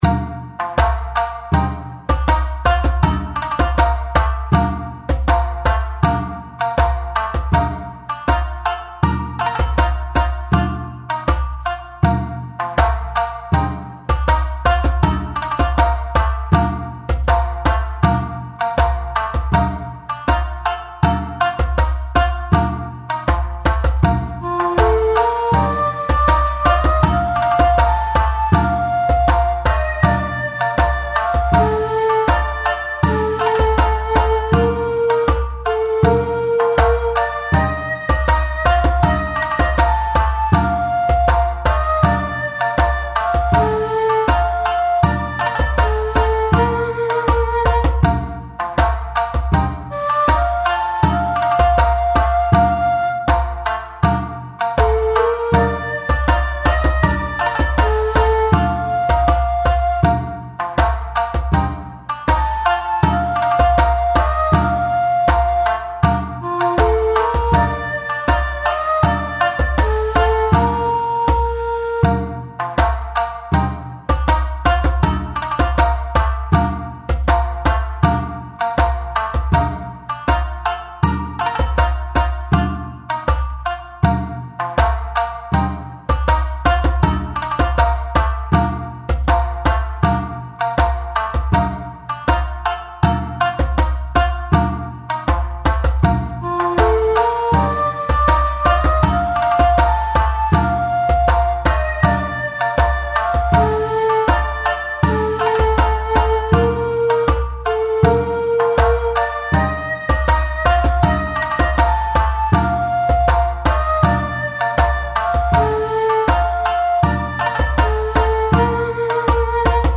BGM
スローテンポ明るい民族